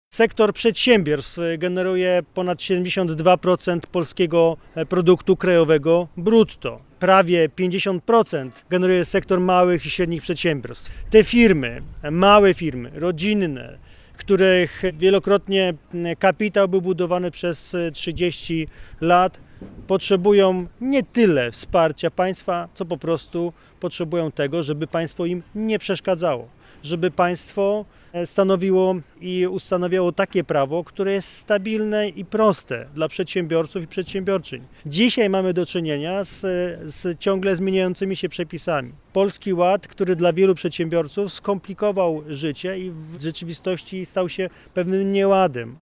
-Przedsiębiorcy nie potrzebują wsparcia państwa, ale prostego i stabilnego prawa, mówi Michał Jaros – Przewodniczący PO na Dolnym Śląsku, Poseł na Sejm RP.